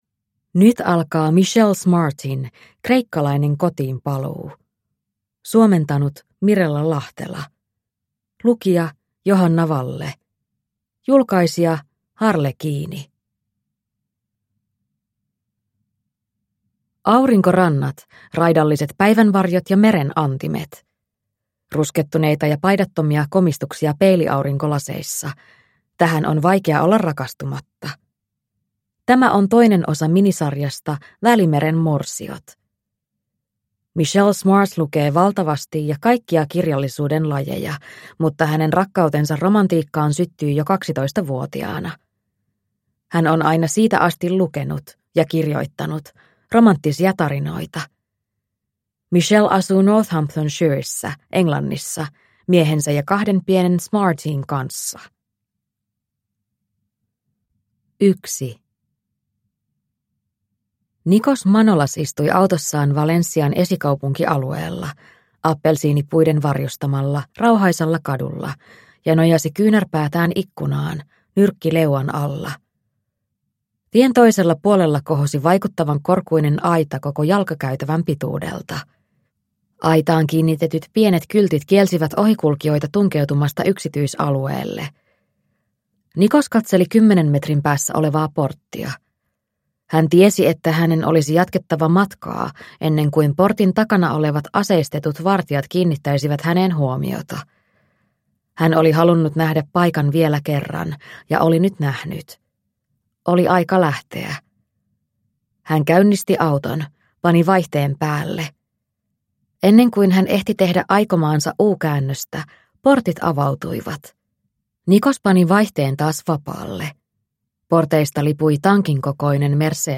Kreikkalainen kotiinpaluu (ljudbok) av Michelle Smart